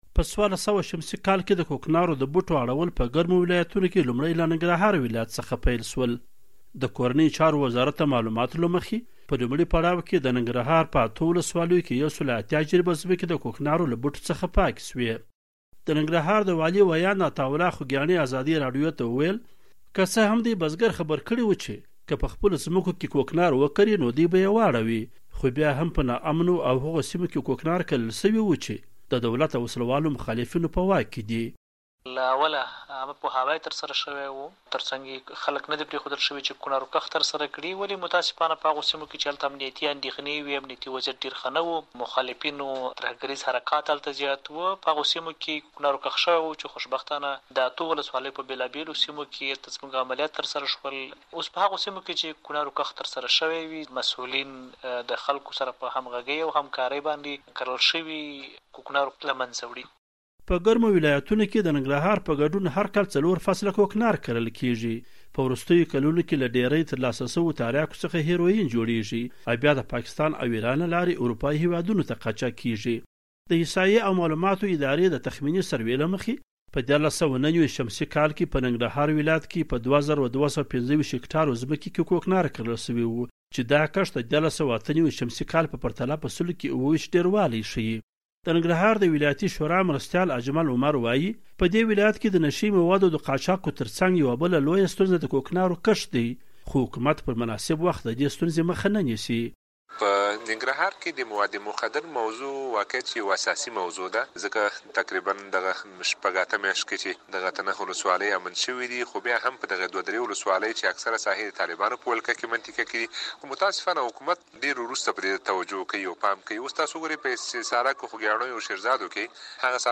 د زهرو کاروان-راپور